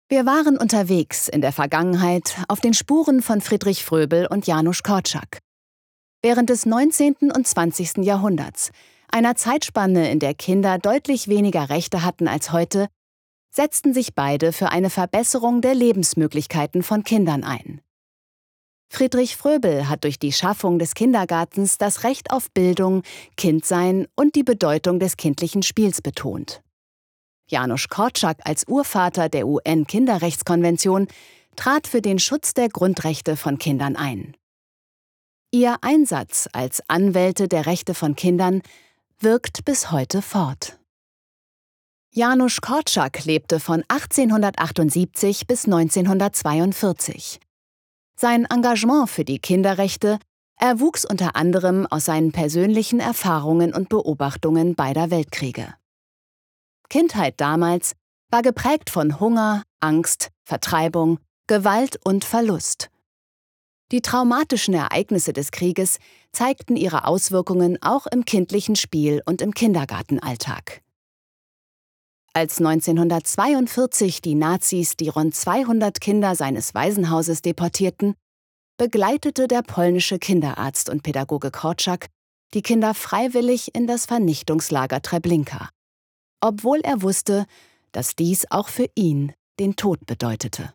Comercial, Accesible, Cálida, Suave, Empresarial
E-learning